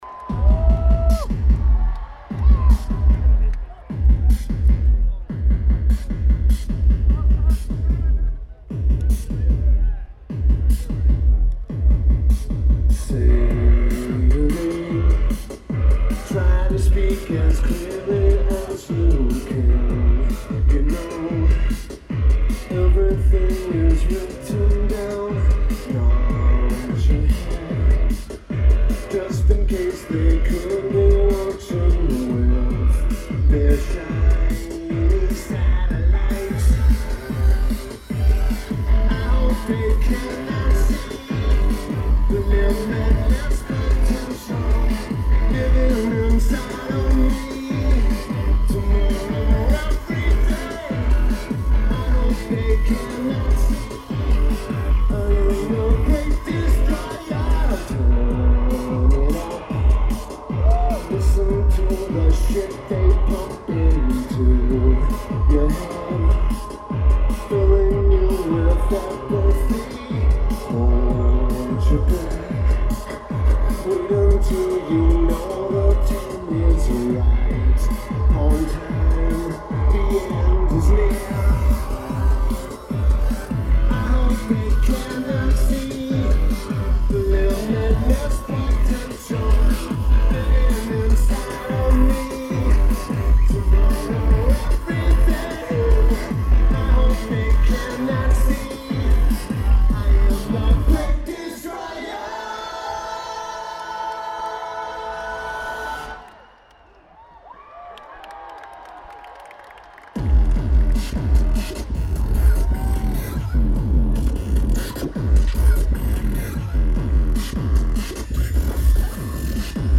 Randall's Island Park
Lineage: Audio - AUD (Zoom H1)